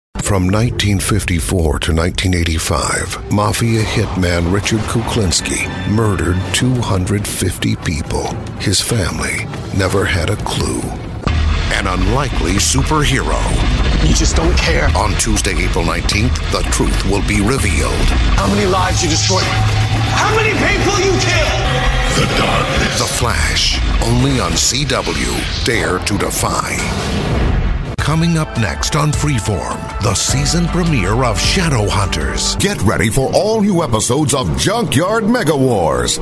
Male
Adult (30-50), Older Sound (50+)
Believable, real, guy-next-door, gravitas, voice of God, friendly, quirky, serious, dramatic, funny, whimsical, magical, relatable, honest, sophisticated, sexy, ardent, warm, fuzzy, clever, professor, Sam Elliott, Berry White, extreme, normal, bizarre, business, narrator, Nat Geo narrator, Mike Rowe, evil, scary, mysterious, blue collar, forceful.
Radio / TV Imaging
Broadcast Promos